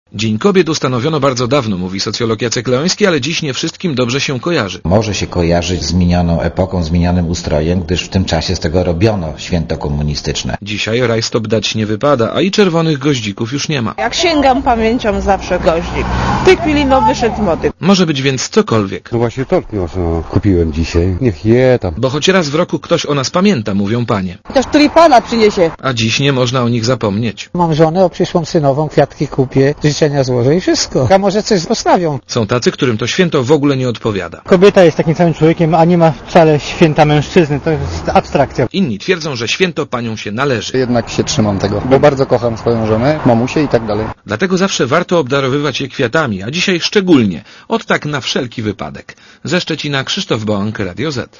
Więcej o Dniu Kobiet w relacji reportera Radia Zet